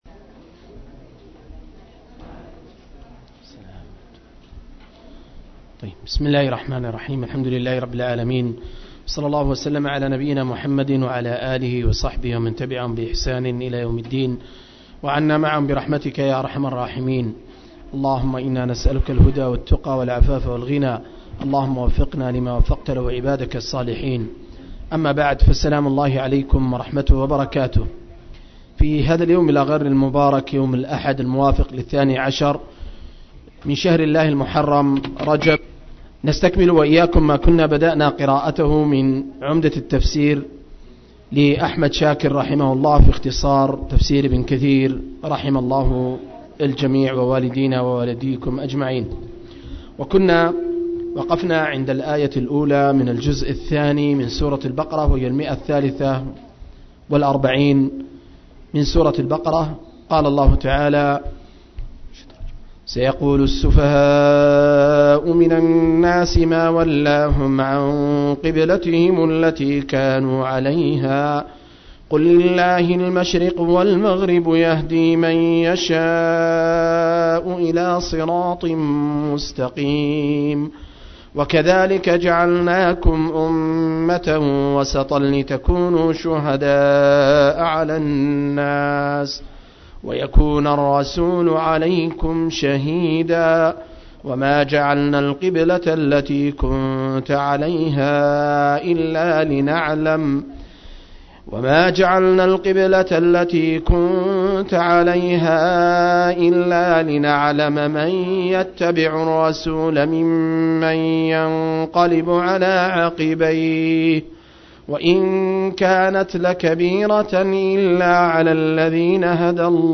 030- عمدة التفسير عن الحافظ ابن كثير – قراءة وتعليق – تفسير سورة البقرة (الآيات 144-142)